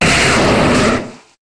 Cri de Rhinoféros dans Pokémon HOME.